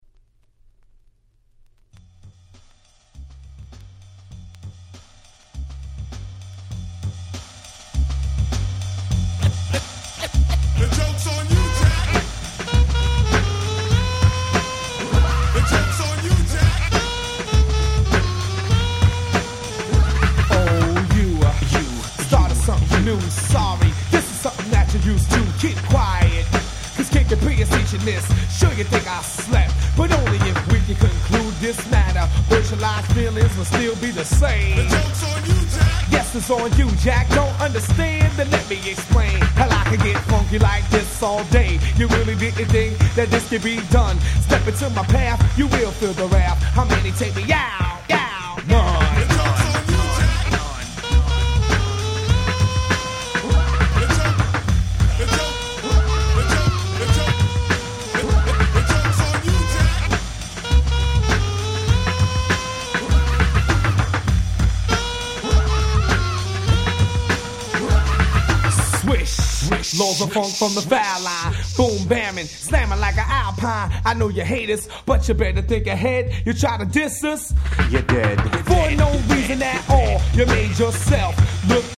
91' Smash Hit Hip Hop !!